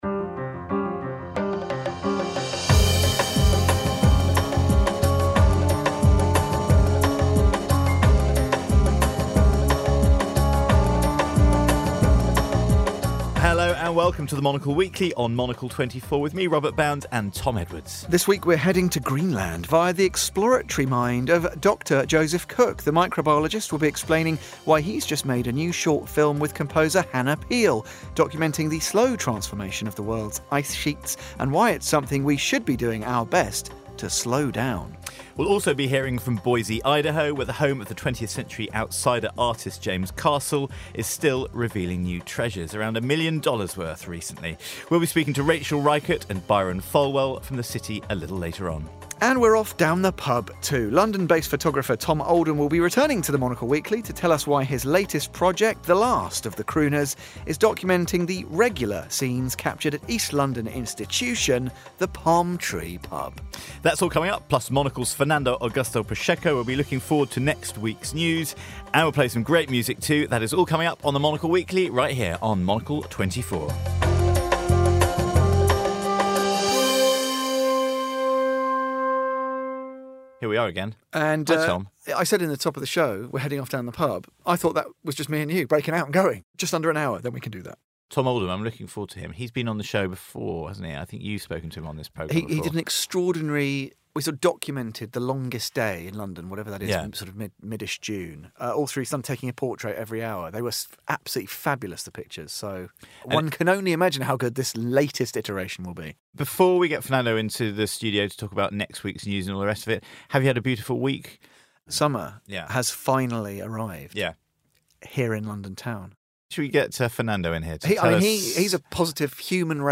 Conversations with authors, artists, and business leaders shaping the world.